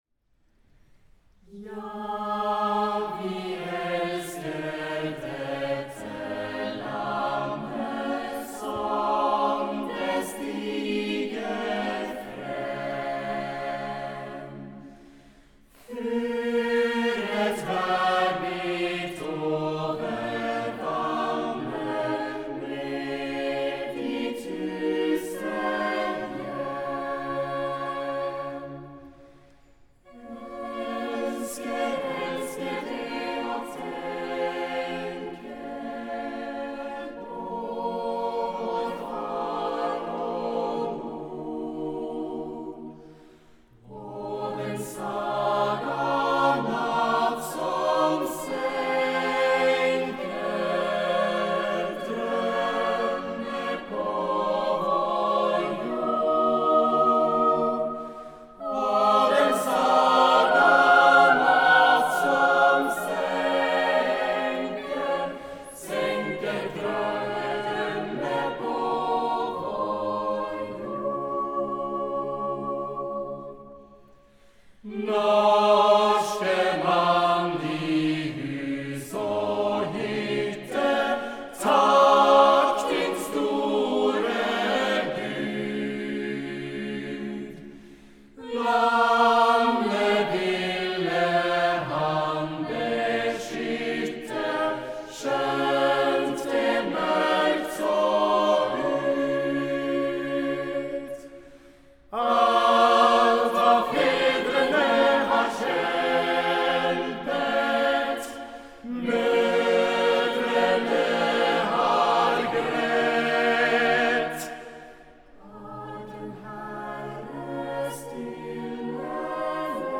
EUROPA CANTAT is an international singing festival at which you can join more than 3000 singers and conductors, composers and choral managers from all parts of Europe and beyond.